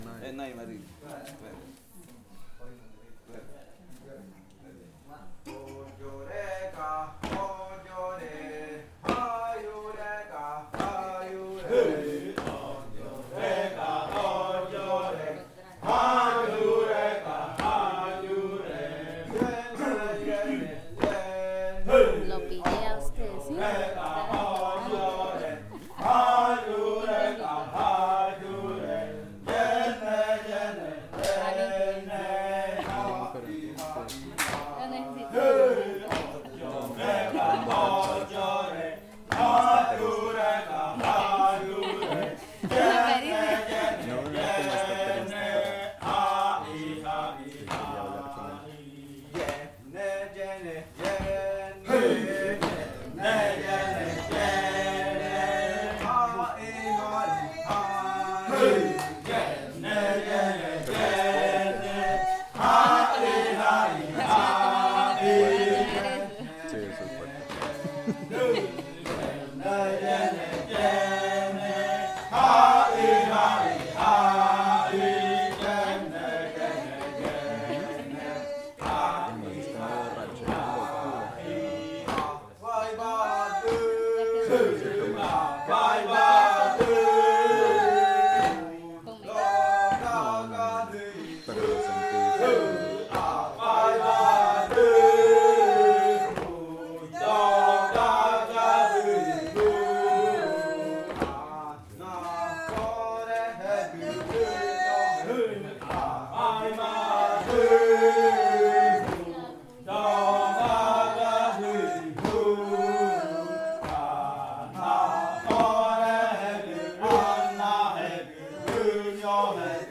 Canto de la variante jaiokɨ
con el grupo de cantores bailando en Nokaido. Este canto hace parte de la colección de cantos del ritual yuakɨ murui-muina (ritual de frutas) del pueblo murui, colección que fue hecha por el Grupo de Danza Kaɨ Komuiya Uai con apoyo de la UNAL, sede Amazonia.
with the group of singers dancing in Nokaido.